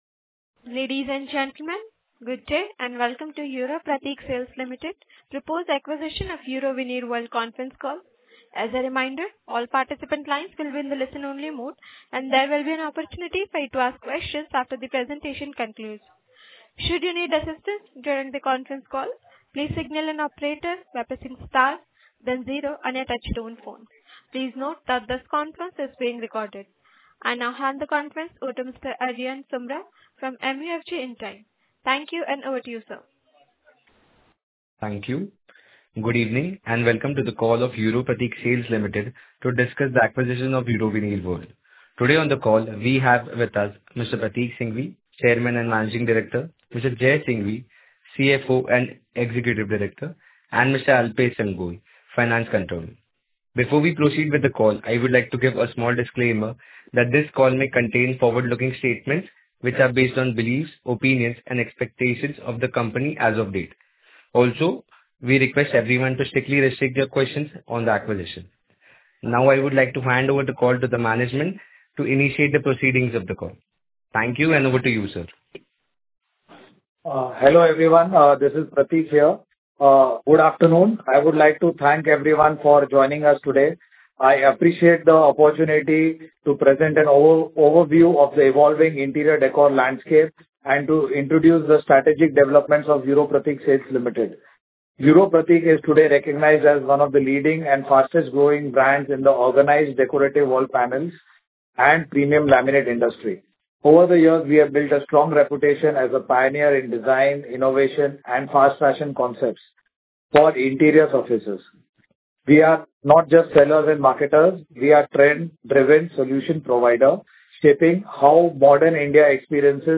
Investor Call – 9 February 2026